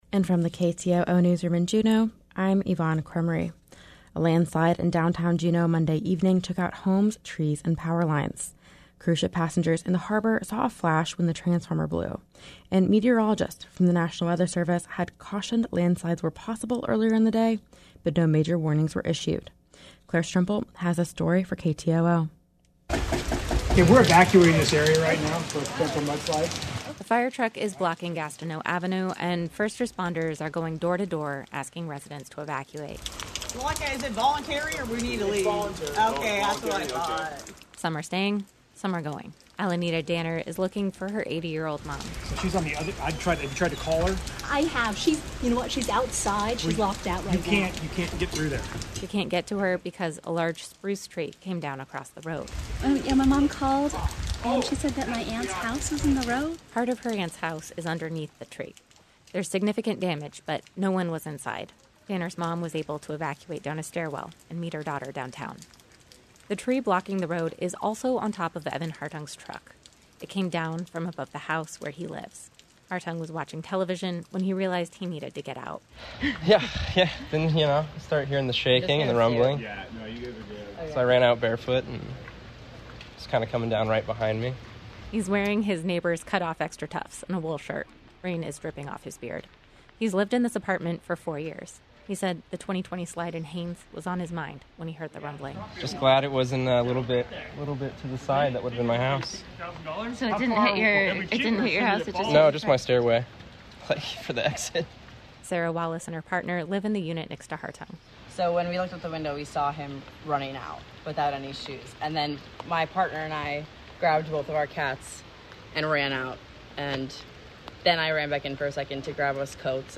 Newscast – Tuesday, Sept. 27, 2022